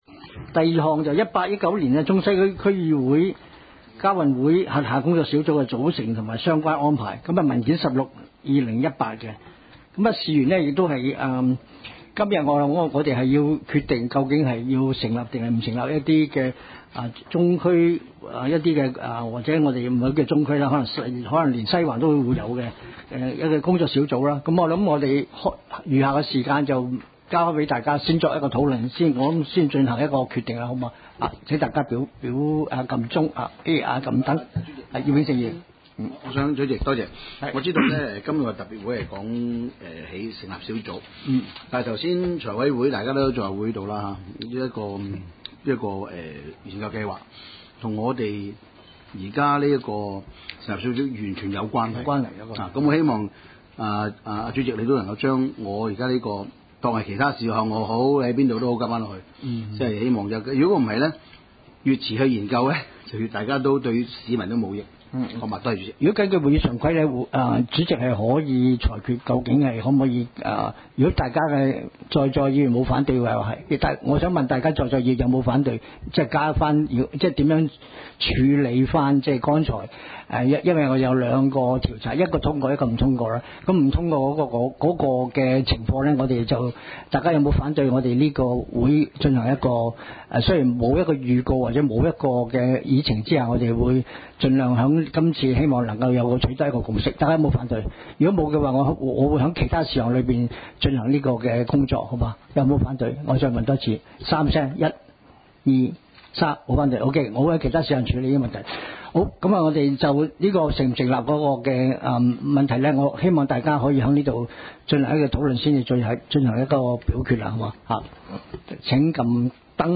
委员会会议的录音记录
地点: 香港中环统一码头道38号 海港政府大楼14楼 中西区区议会会议室